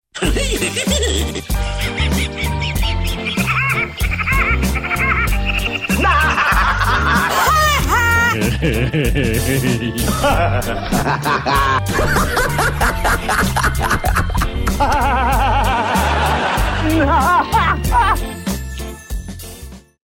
LAUGHS MONTAGE